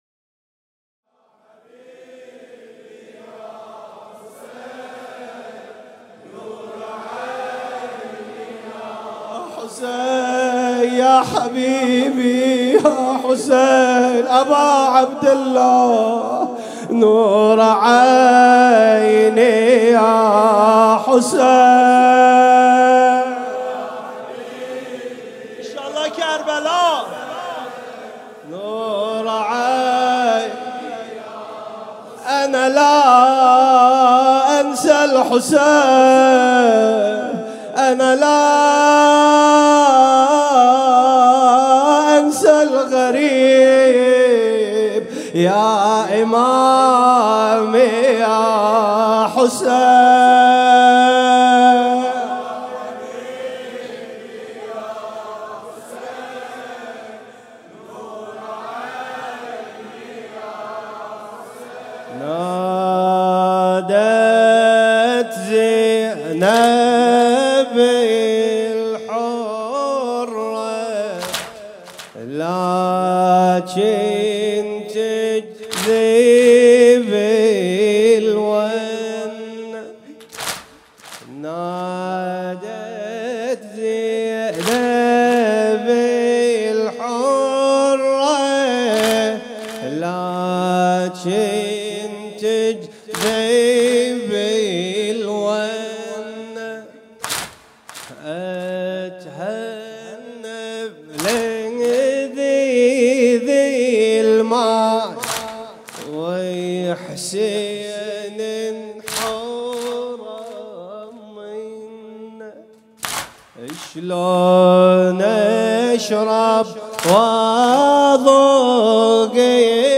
2 صفر 97 - هیئت شبان القاسم - نادت زینب الحرة